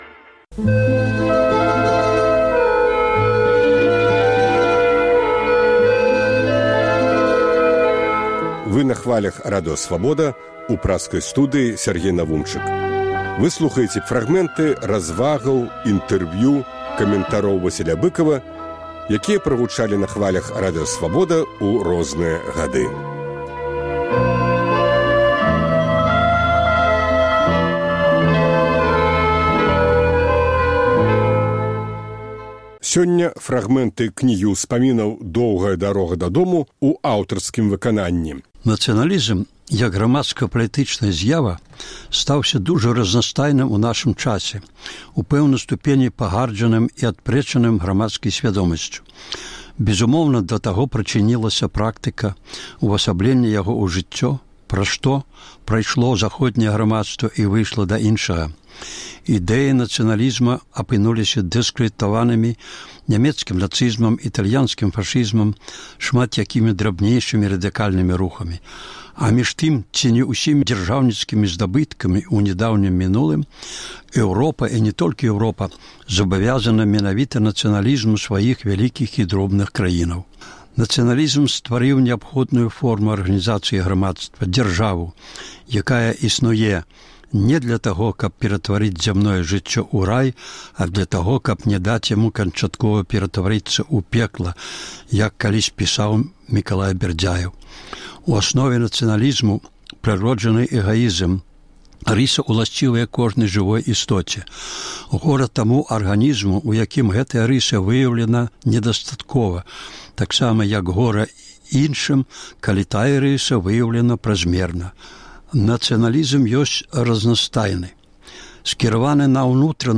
У чытальнай залі Свабоды - успаміны ў аўтарскім чытаньні.